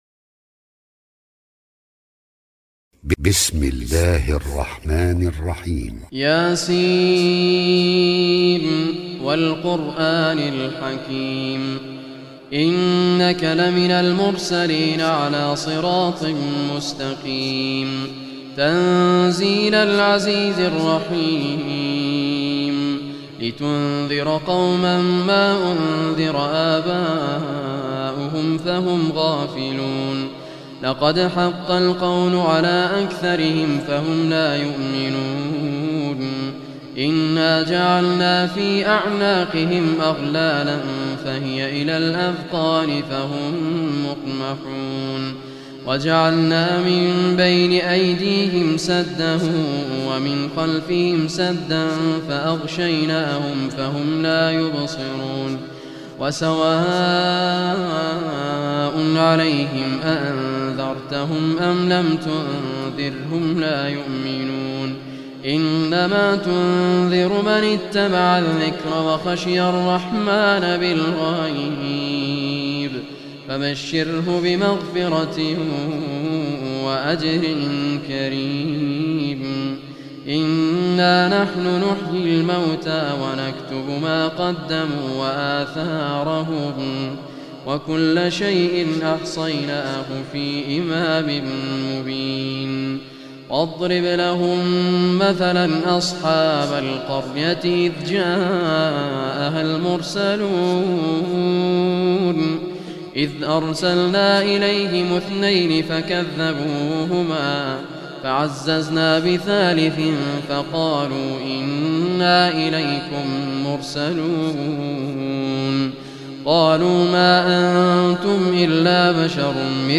Surah Yaseen Recitation by Sheikh Raad a Kurdi
Surah Yaseen, listen or play online mp3 tilawat / recitation in Arabic in the beautiful voice of Sheikh Raad al Kurdi.